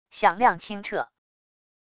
响亮清澈wav音频